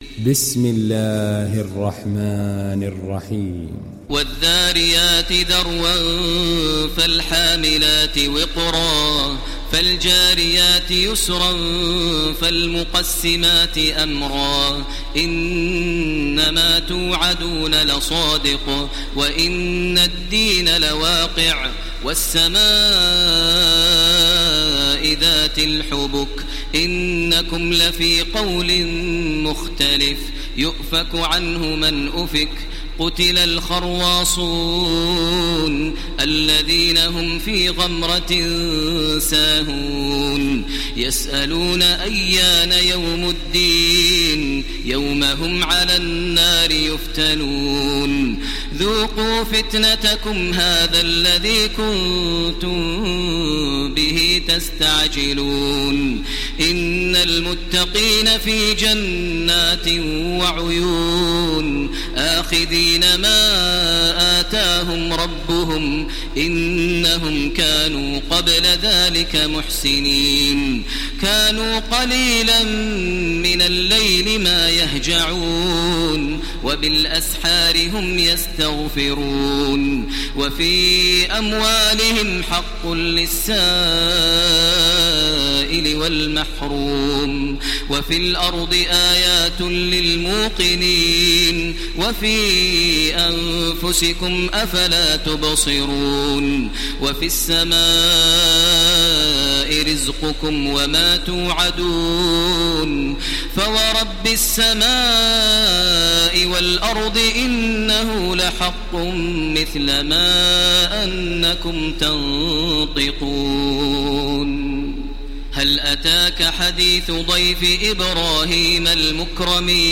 دانلود سوره الذاريات mp3 تراويح الحرم المكي 1430 روایت حفص از عاصم, قرآن را دانلود کنید و گوش کن mp3 ، لینک مستقیم کامل
دانلود سوره الذاريات تراويح الحرم المكي 1430